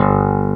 CLAV2SFTG1.wav